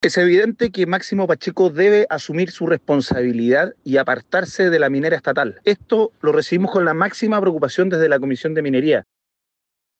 En contraste, desde la comisión de Minería de la Cámara, el diputado republicano Benjamín Moreno sostuvo que Pacheco debería dejar su cargo a disposición mientras se investigan las responsabilidades.